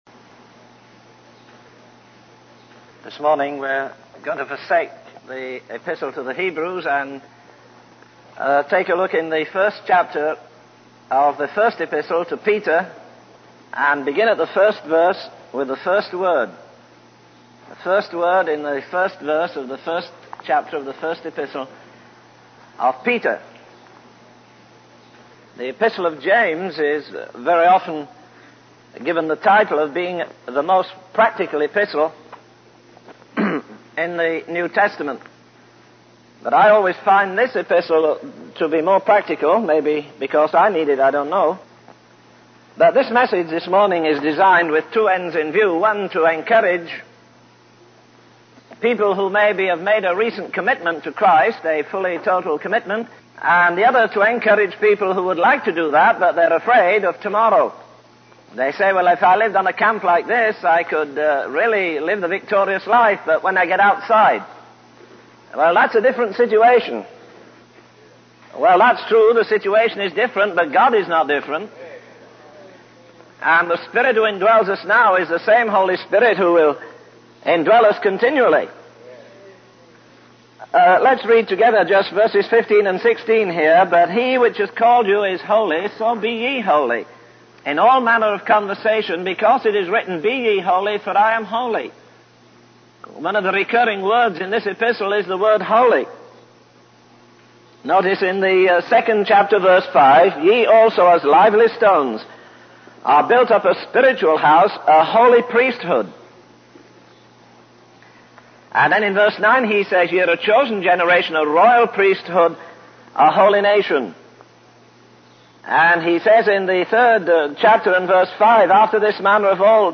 In this sermon, the preacher discusses the story of Job and how he faced immense suffering and loss.